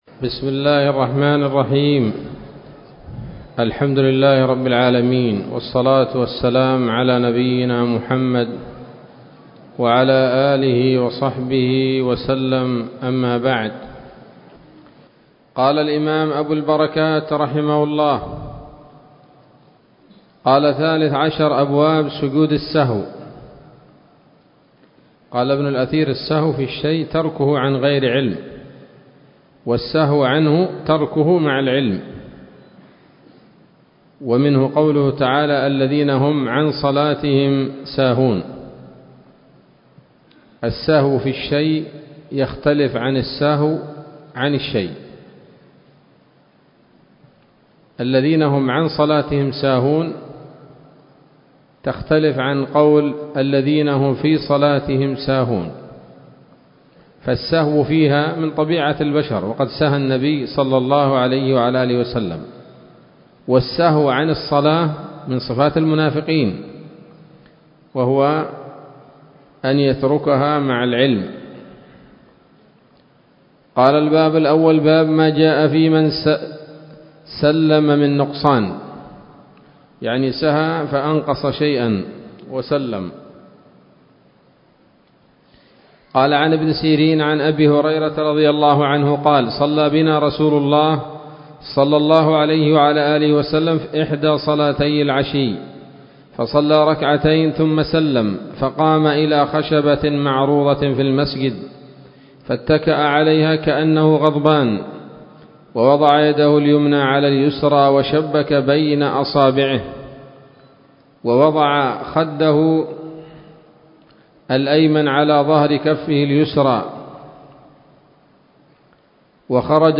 الدرس الأول من ‌‌‌‌أبواب سجود السهو من نيل الأوطار